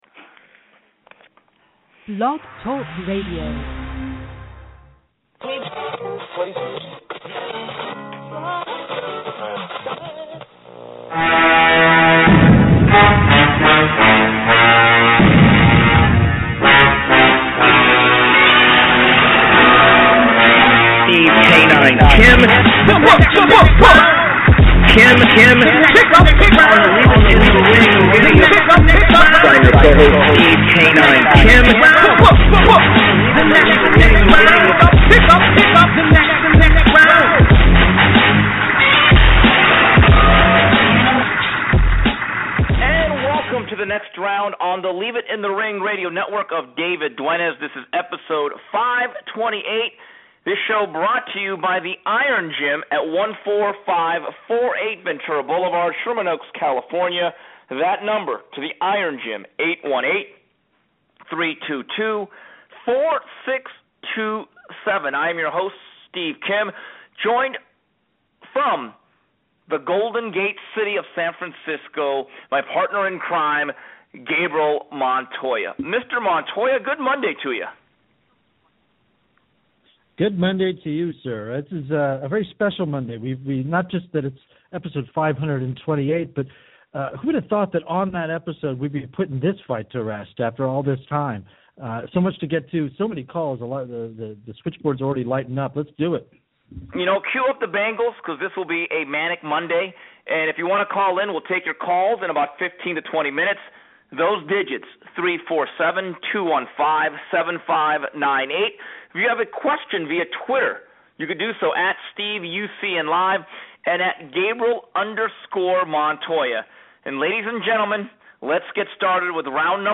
And as always, news, notes and your calls.